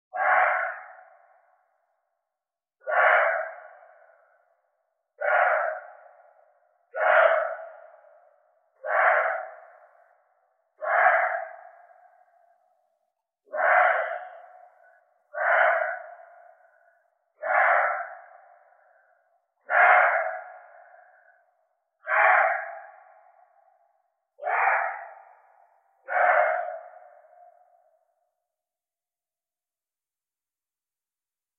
Лающий звук лани